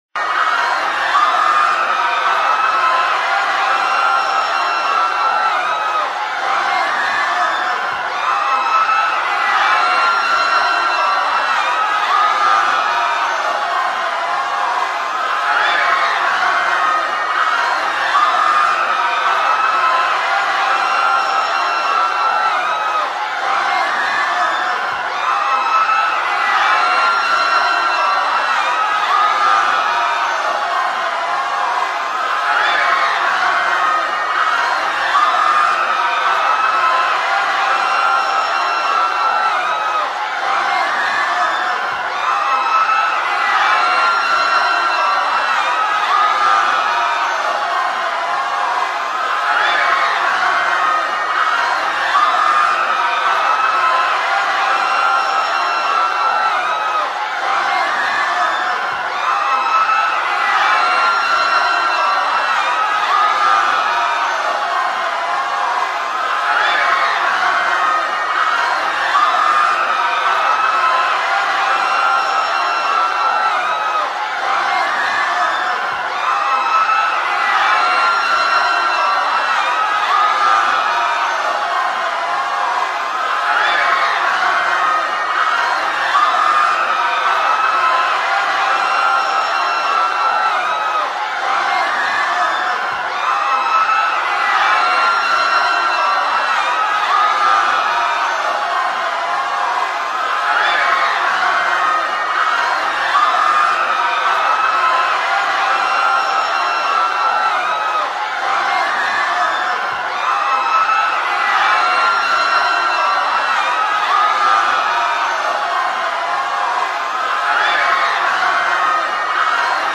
دانلود آهنگ هرج و مرج از افکت صوتی انسان و موجودات زنده
دانلود صدای هرج و مرج از ساعد نیوز با لینک مستقیم و کیفیت بالا
جلوه های صوتی